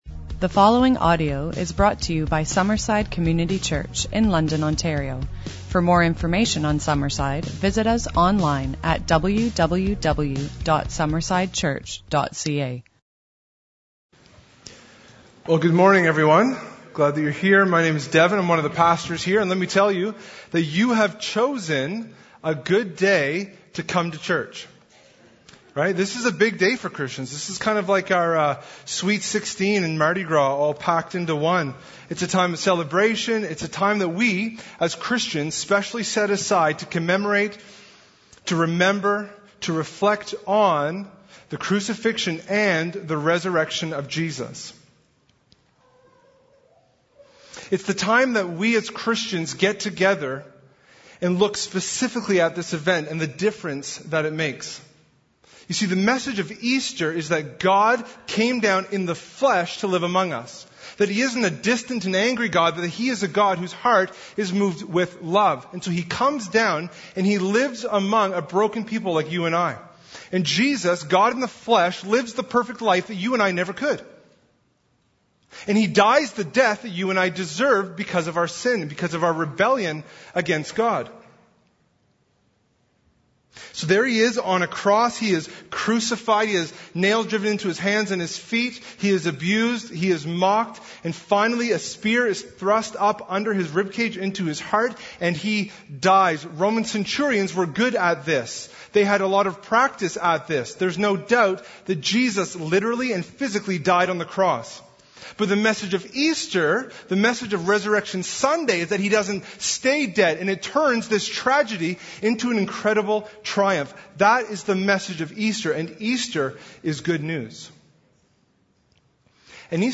Easter Service 2017